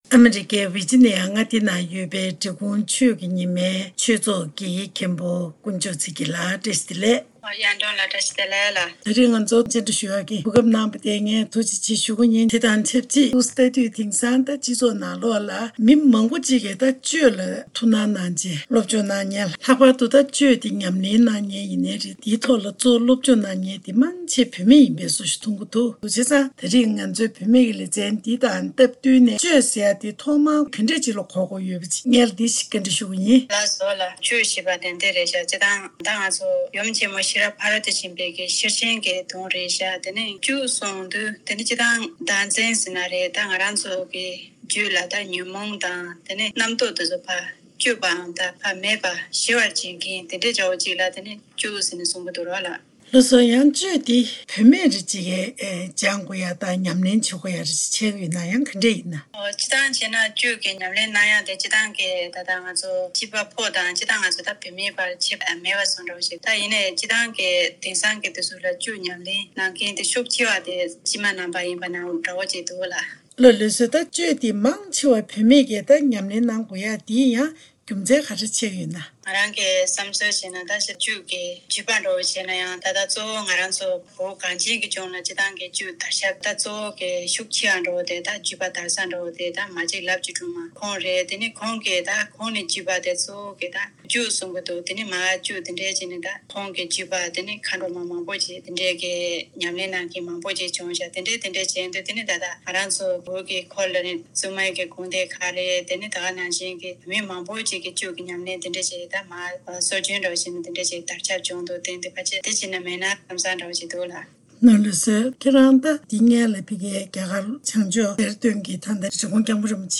གནས་འདྲི་ཞུས་པ་ཞིག